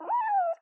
Звуки мяуканья котенка